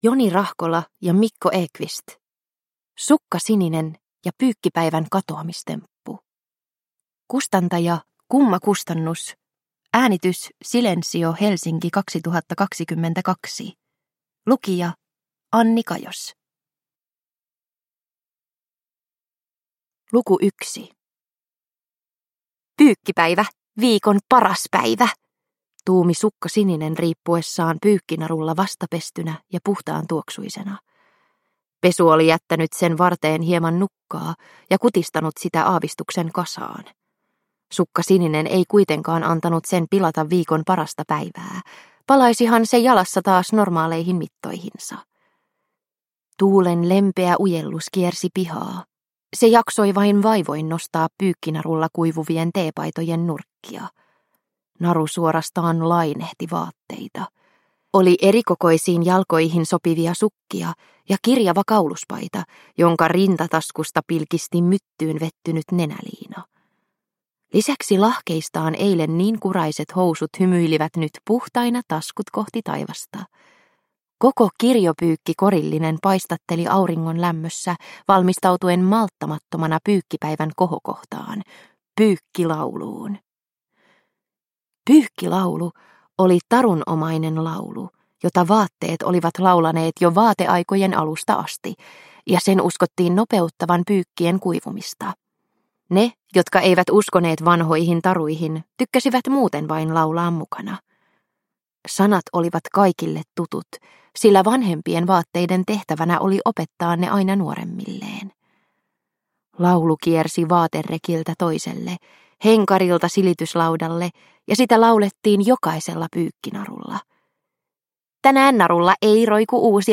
Sukka Sininen ja pyykkipäivän katoamistemppu (ljudbok) av Joni Rahkola